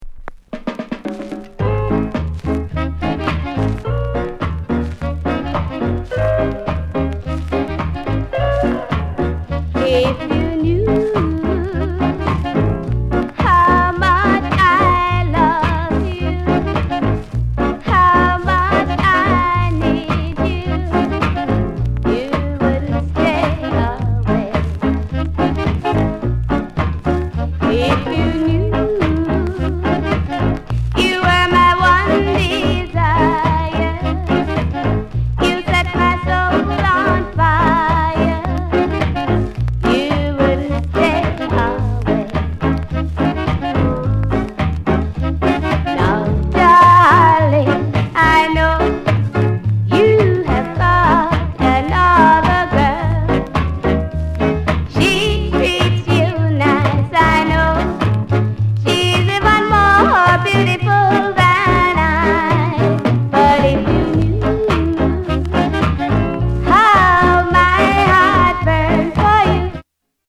SOUND CONDITION A SIDE VG(OK)
NICE ROCKSTEADY